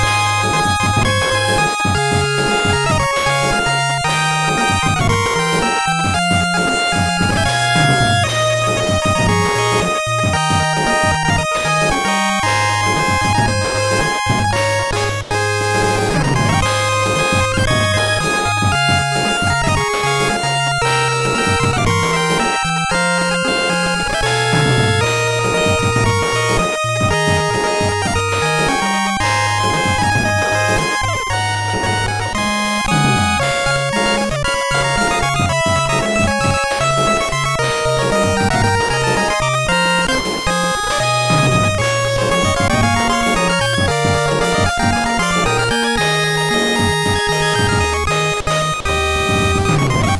NES